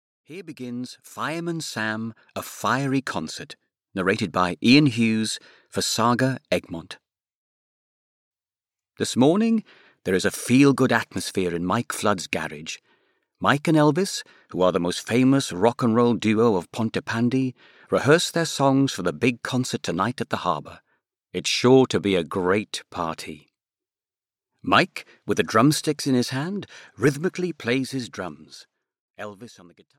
Audio knihaFireman Sam - A Fiery Concert (EN)
Ukázka z knihy